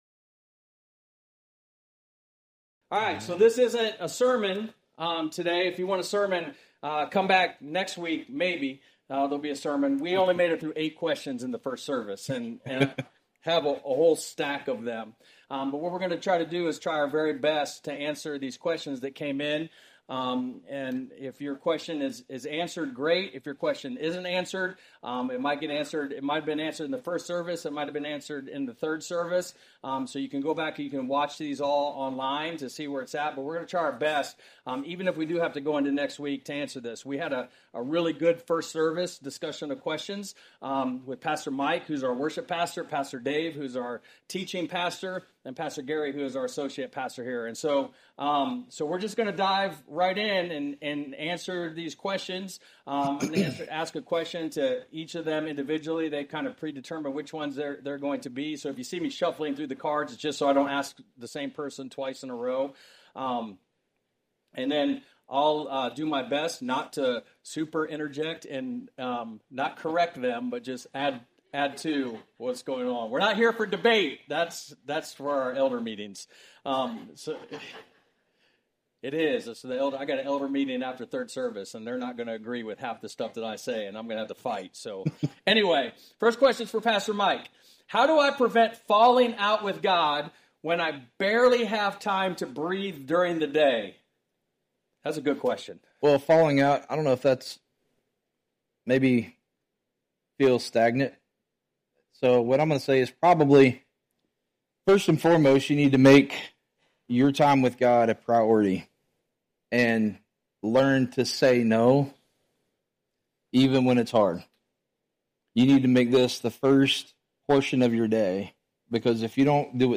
Q&A - 9:30 Service
This week, we had a special presentation of questions and answers by our pastors.